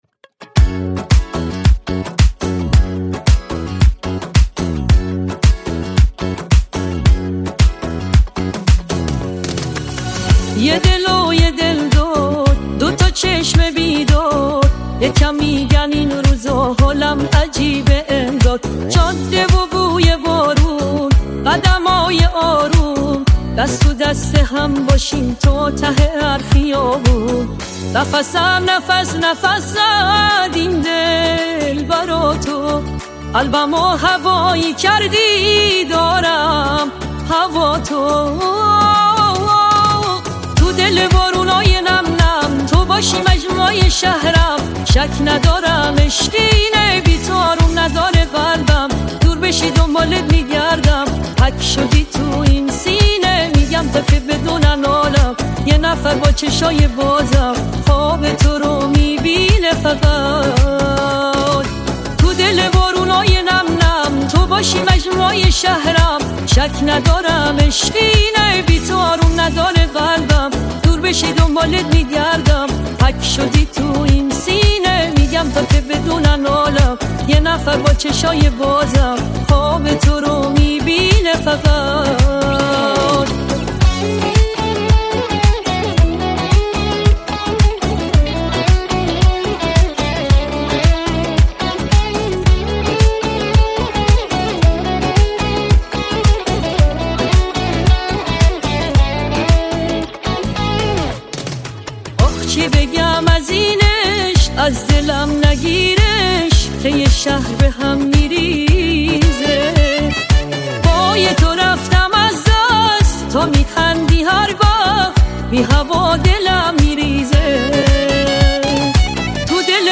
ژانر: پاپ / رپ